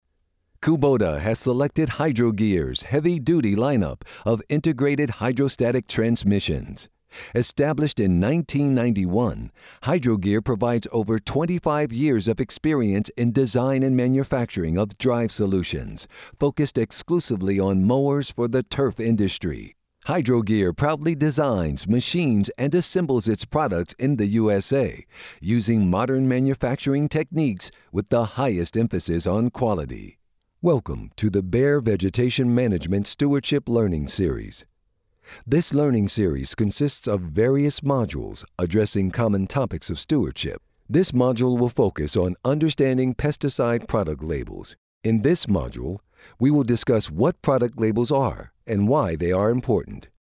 English (American)
E-learning
Middle-Aged
Senior
CoolIntelligentBelievableRealClearConversationalFriendlyMatureNeutralVersatile